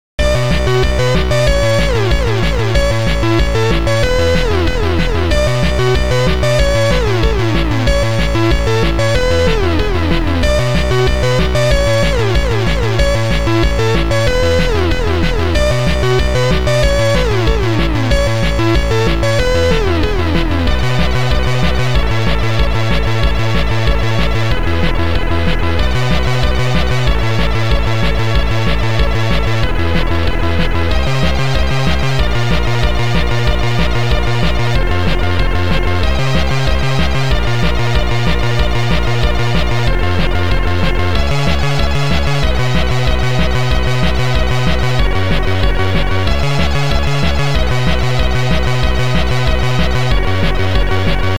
Keygen Music
chiptunes